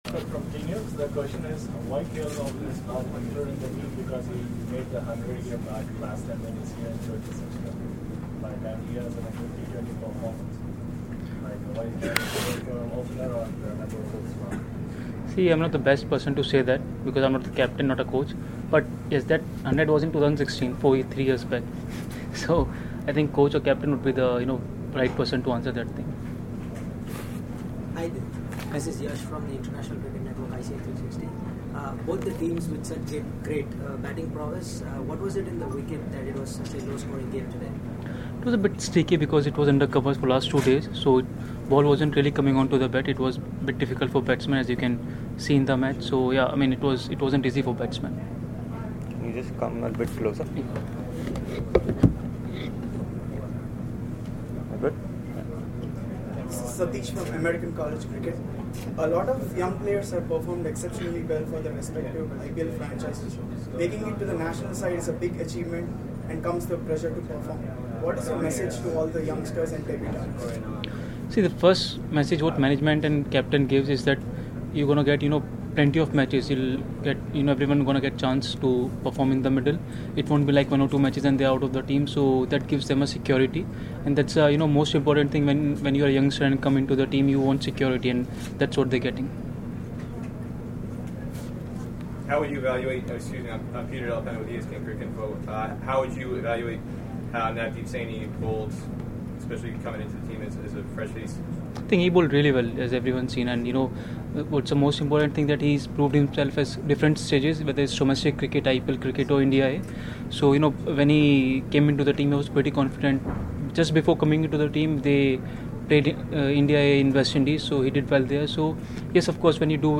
Bhuvneshwar Kumar, Member, Indian Cricket Team. He spoke to the media in Florida on Saturday after the 1st T20I against West Indies.